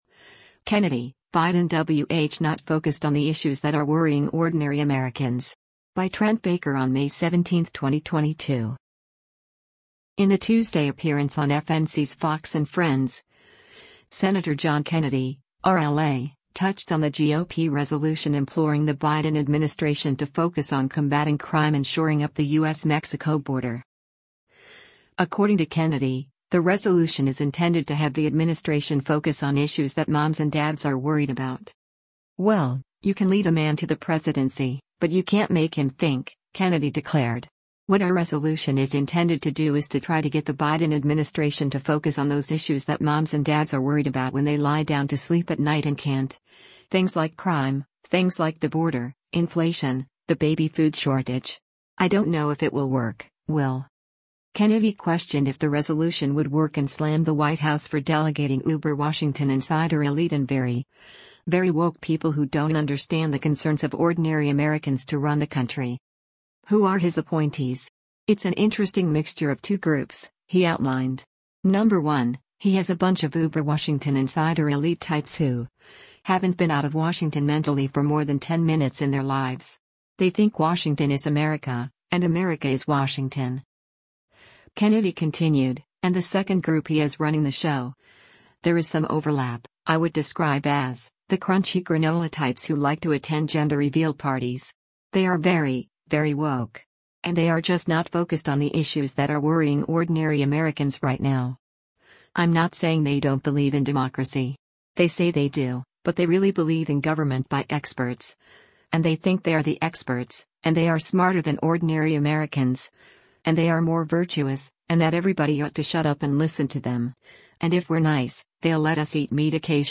In a Tuesday appearance on FNC’s “Fox & Friends,” Sen. John Kennedy (R-LA) touched on the GOP resolution imploring the Biden administration to focus on combating crime and shoring up the U.S.-Mexico border.